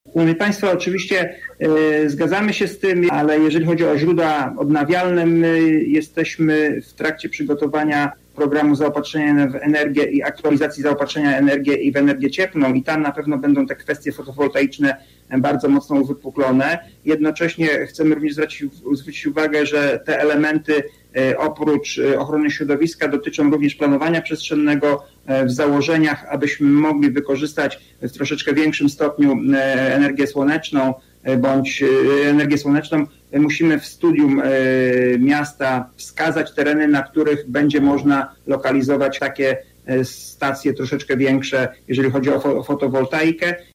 Mówi Bożena Ronowicz, radna klubu Prawo i Sprawiedliwość: